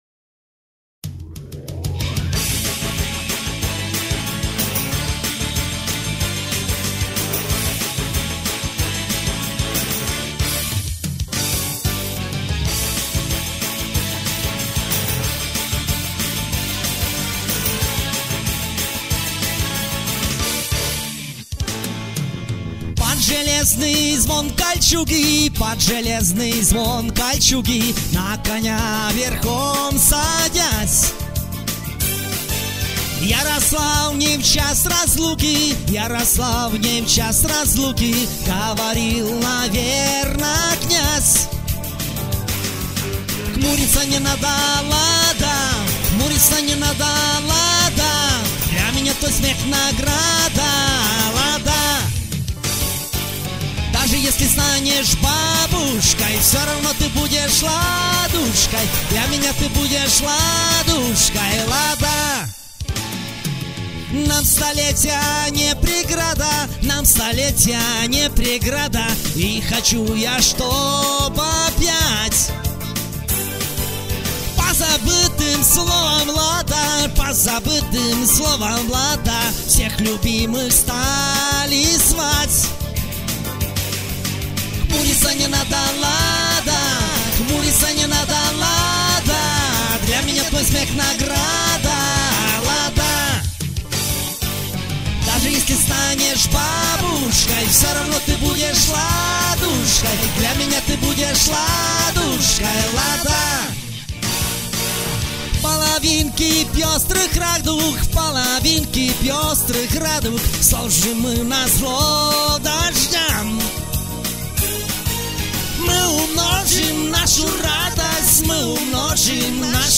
В стиле рок-н-ролла!